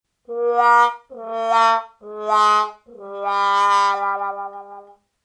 wah-wah.ogg